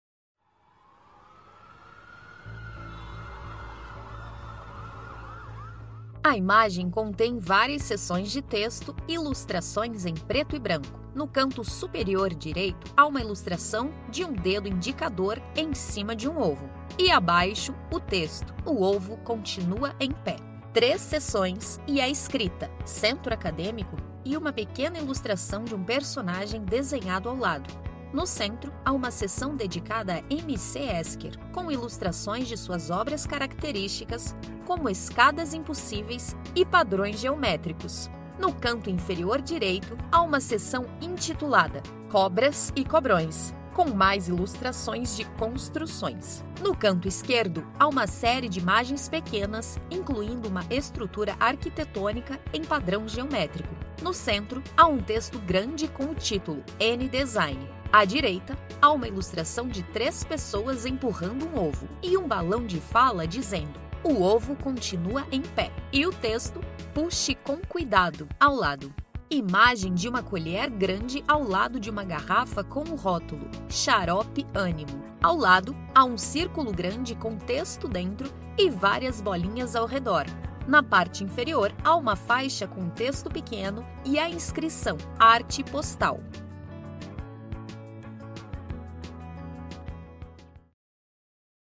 Audiodescrição do Fanzine n° 3